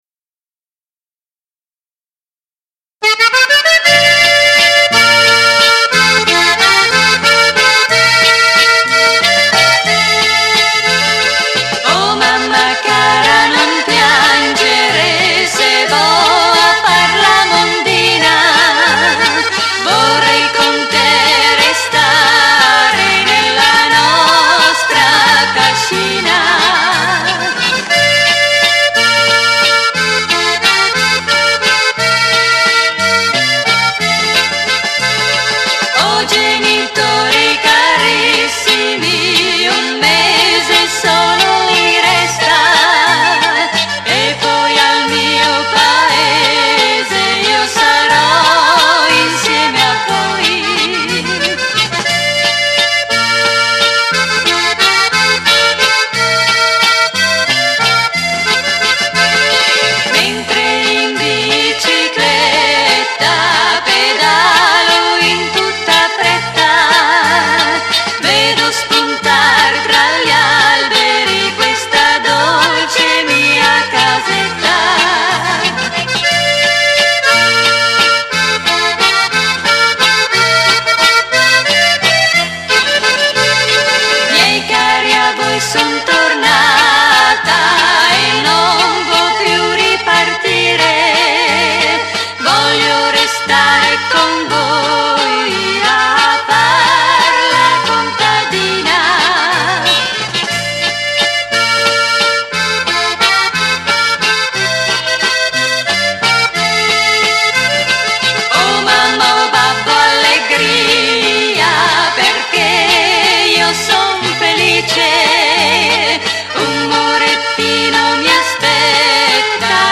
CANTO POPOLARE